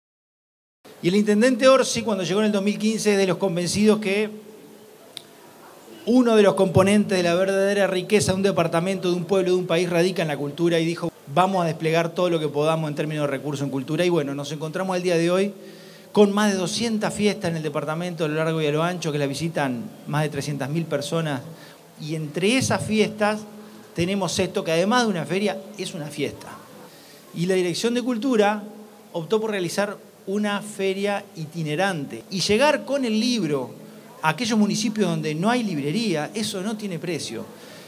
La ceremonia de inauguración contó con la presencia del Secretario General de la Intendencia de Canelones, Dr. Esc. Francisco Legnani, que resaltó el progreso del Gobierno de Canelones en políticas culturales a lo largo de 15 años.